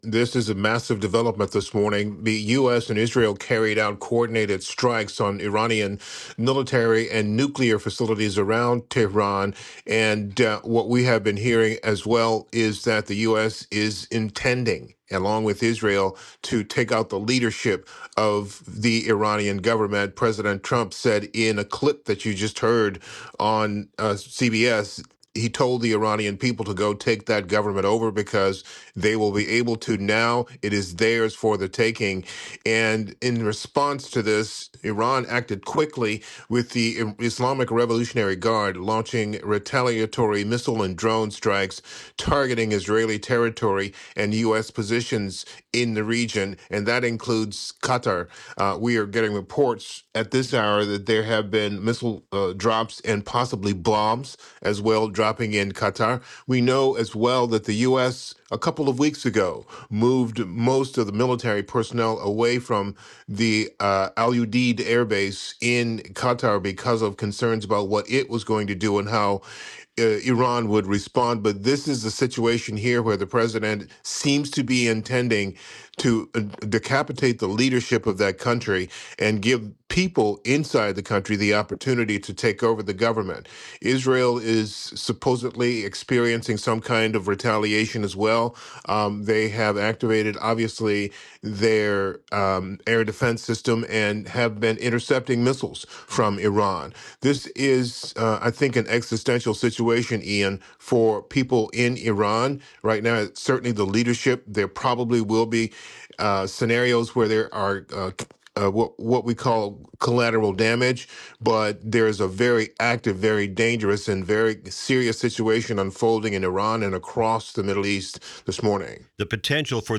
This interview has been lightly edited for clarity.